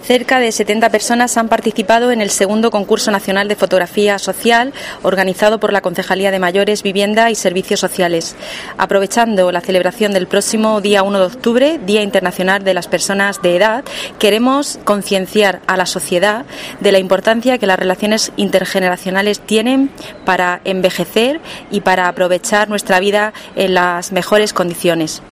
Paqui Pérez, concejala de Mayores, Vivienda y Servicios Sociales